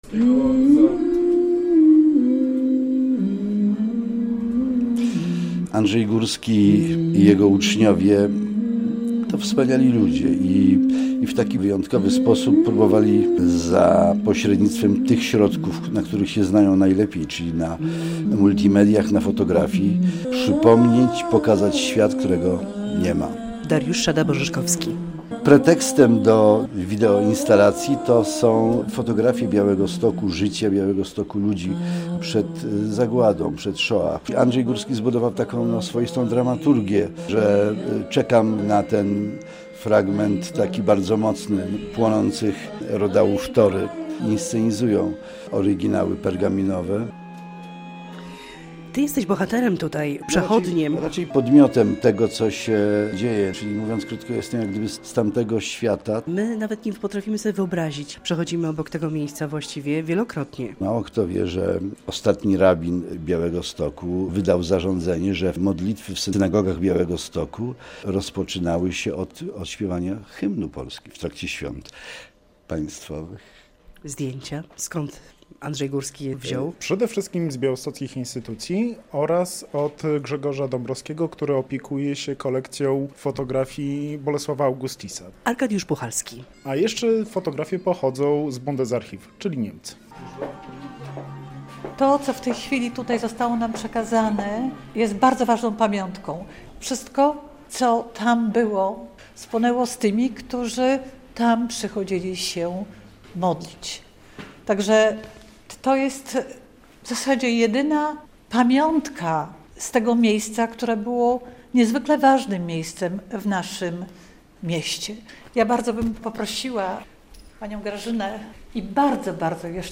82. rocznica spalenia Wielkiej Synagogi w Białymstoku - relacja